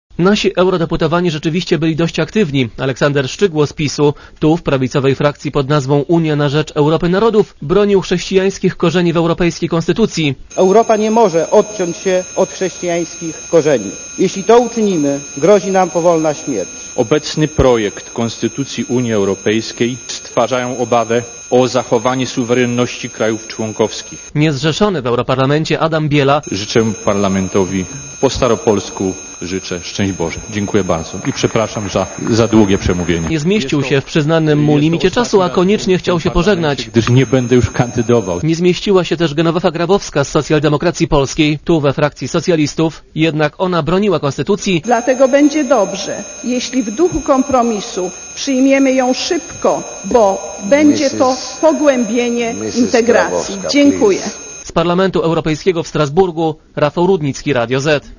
Polscy eurodeputowani po raz pierwszy wzięli we wtorek udział w zwyczajnej debacie plenarnej parlamentu UE, świeżo poszerzonej o 10 krajów, w tym o Polskę. Ci z prawicy upominali się zwłaszcza o odwołanie do korzeni chrześcijańskich Europy.